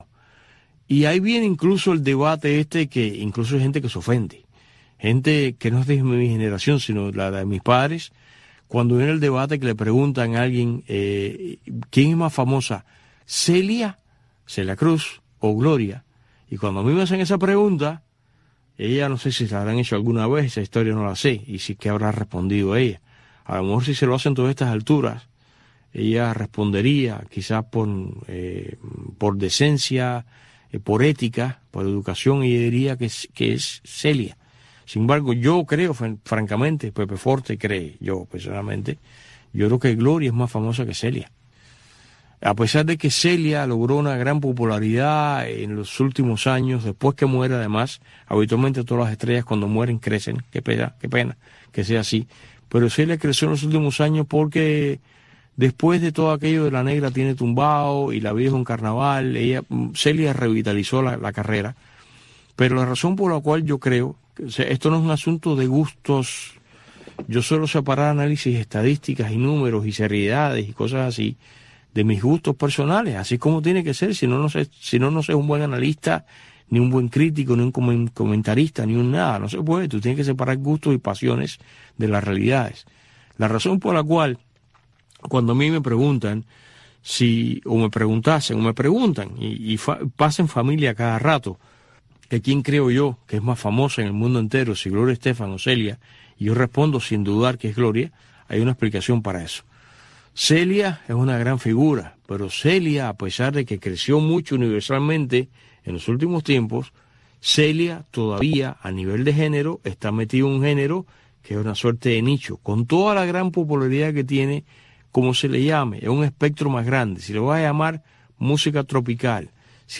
conecta cada día con sus invitados en la isla en este espacio informativo en vivo, que marca el paso al acontecer cubano.